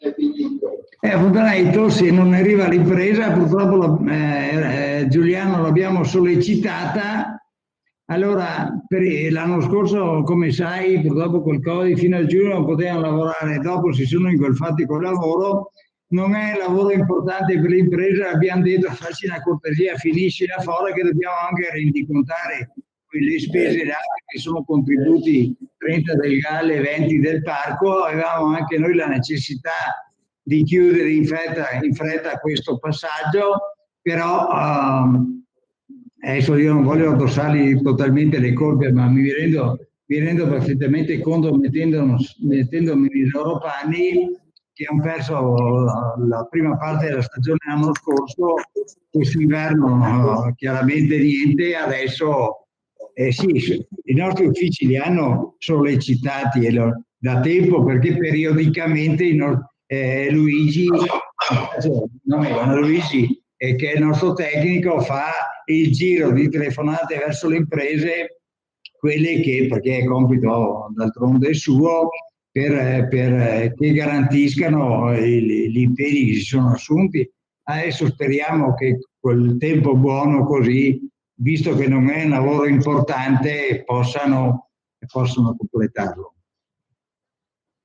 DAL CONSIGLIO COMUNALE IL SINDACO NINO DEON SULLA FONTANA DI TOS